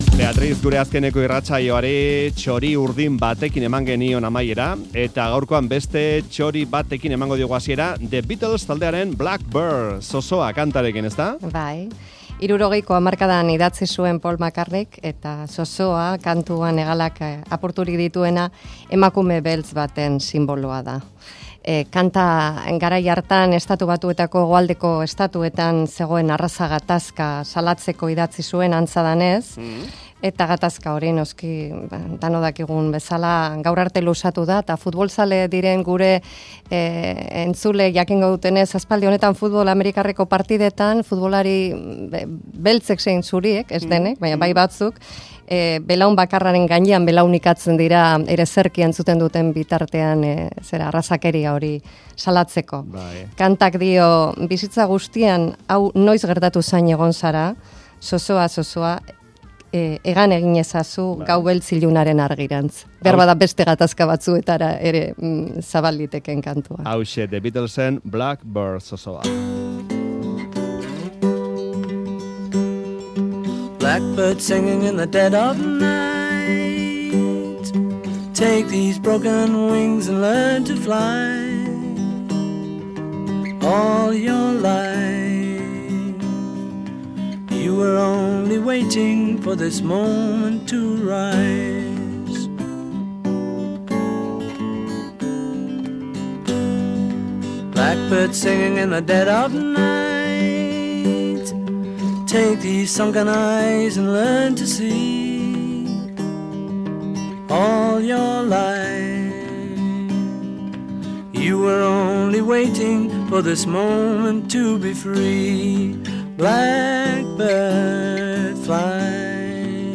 Realaren ereserkia kantatzen duen loroa